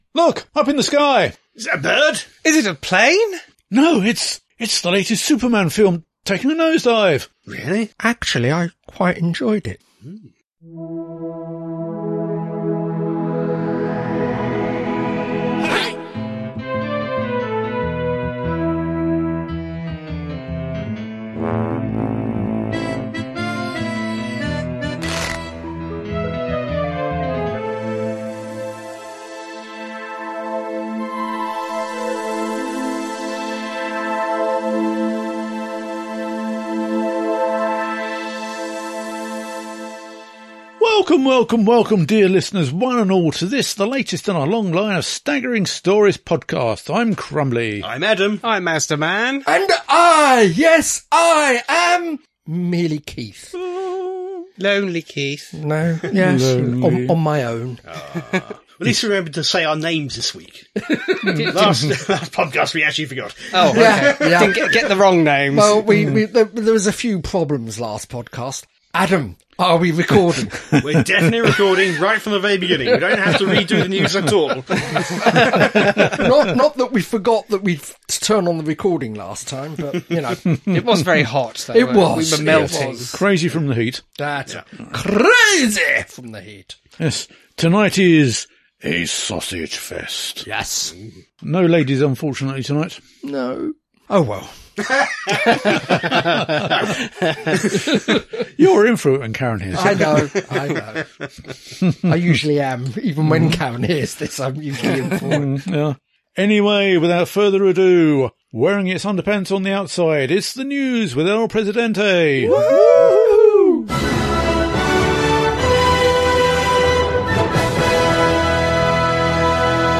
00:00 – Intro and theme tune.
48:51 — End theme, disclaimer, copyright, etc.